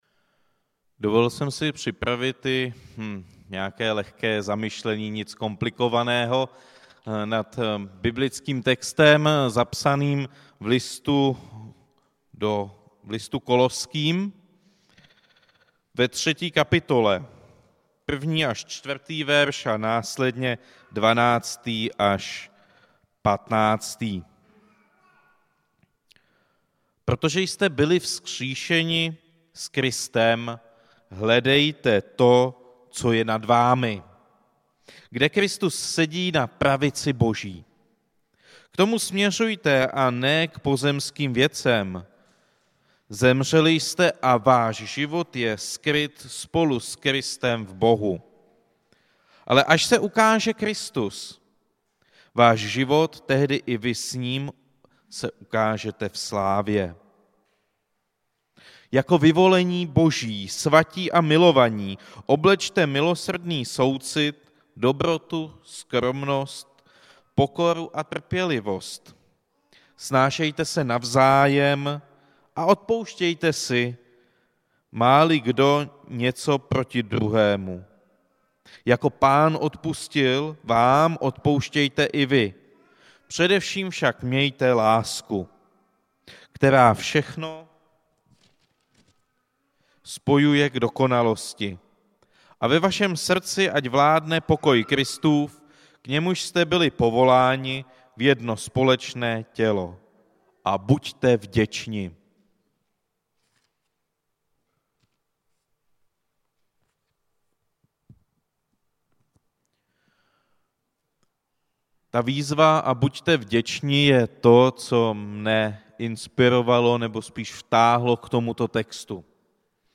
Kázání
Událost: Kázání